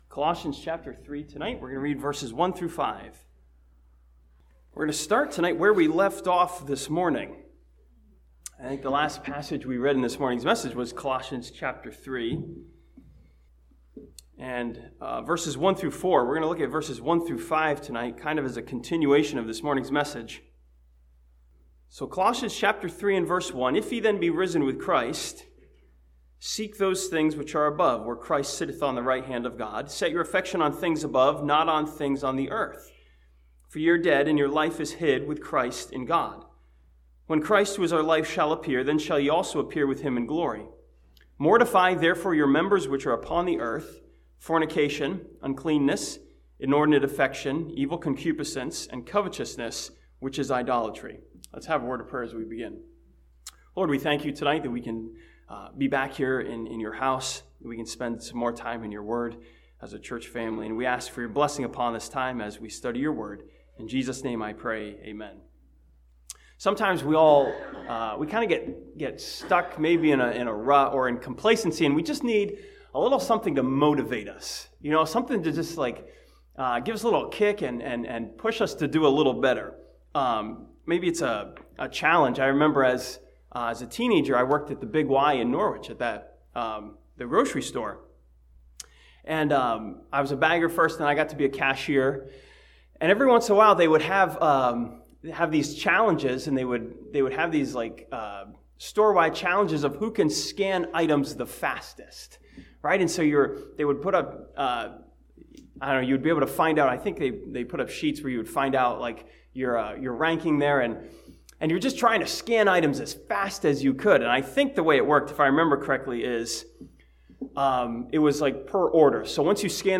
This sermon from Colossians chapter 3 challenges us to aim high and seek those things which are above.